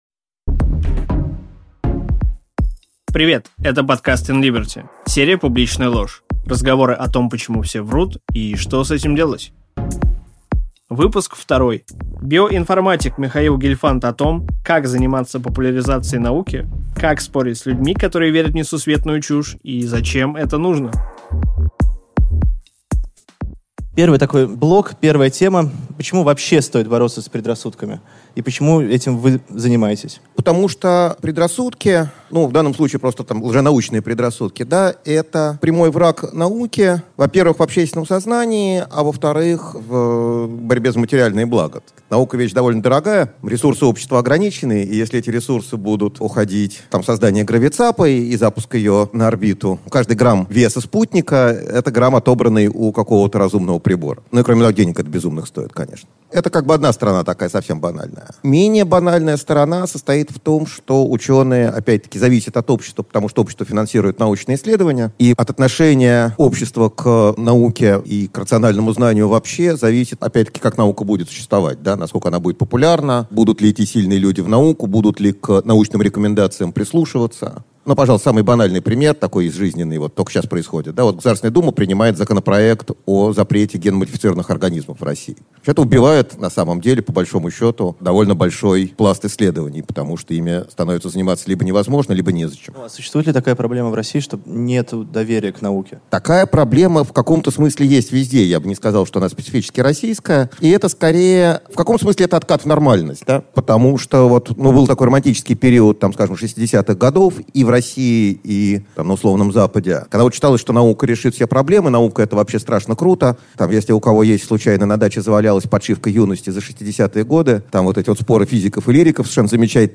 Аудиокнига Борьба с предрассудками | Библиотека аудиокниг